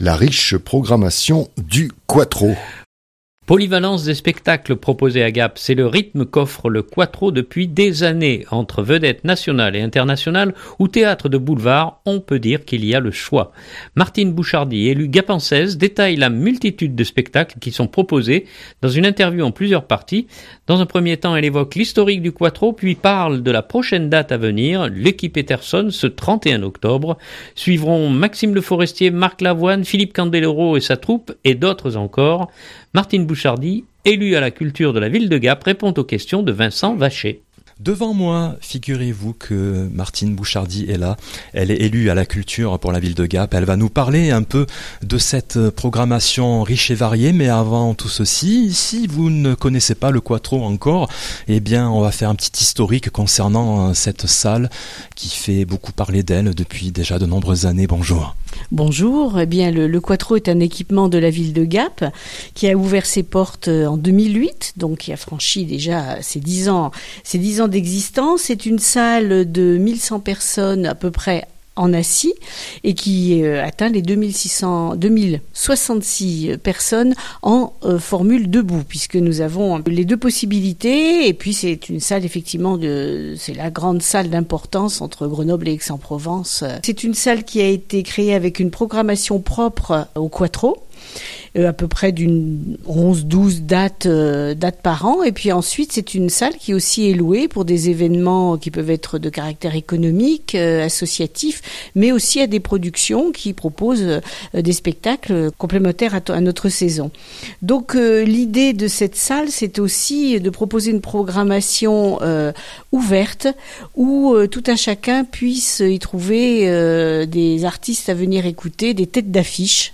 Martine Bouchardy, élue gapençaise détaille la multitude de spectacles qui sont proposés dans une interview en plusieurs parties. Dans un premier temps elle évoque l’historique du Quattro, puis parle de la prochaine date à venir Lucky Peterson ce 31 octobre.